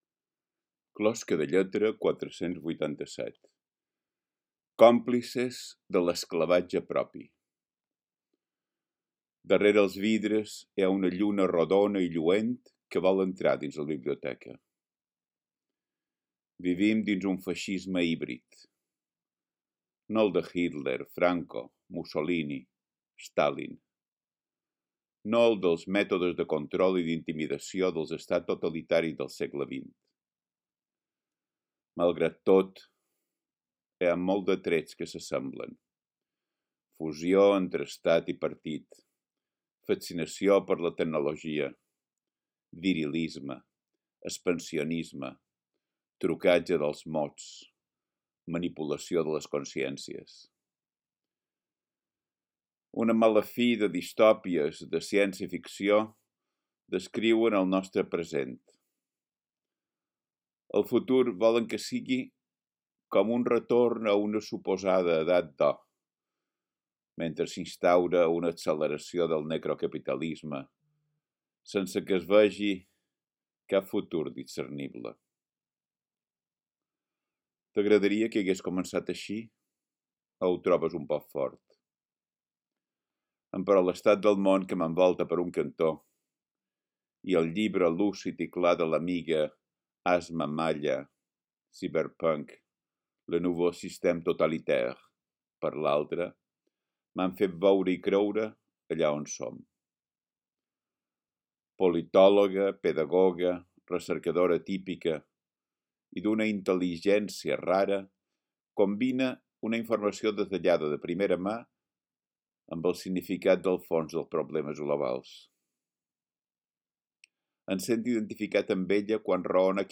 Podeu escoltar el text recitat per Biel Mesquida mateix: